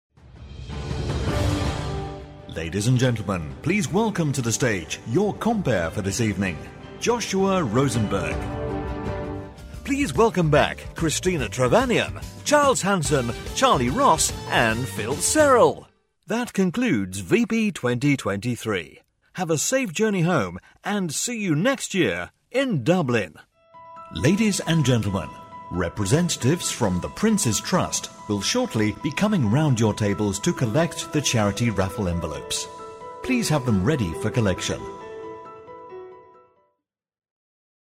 Older Sound (50+)
Warm, articulate British voice with natural authority and clean, confident delivery.
Live Announcer
A Mix Of Live Vogs.